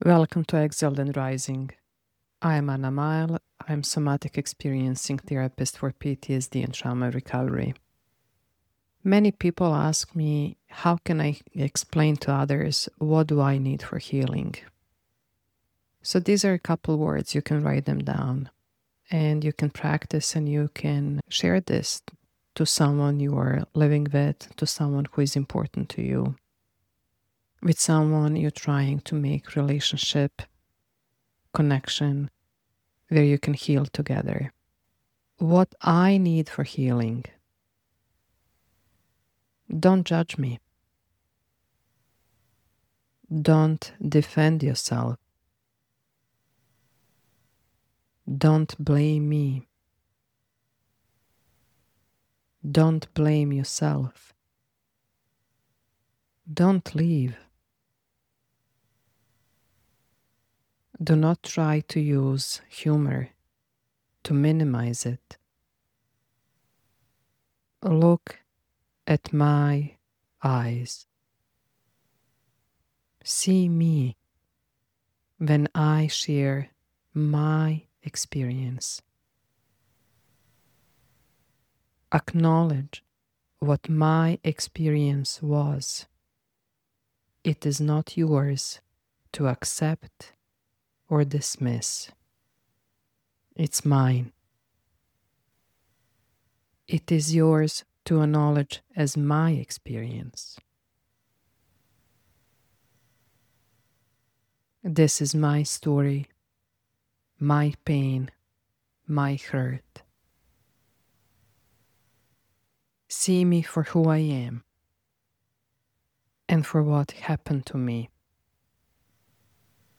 • Safety through voice and rhythm.
The steady repetition is itself a regulation tool. Each instruction is short, predictable, and calm — an auditory anchor for the nervous system.
• Voice of authority and intimacy.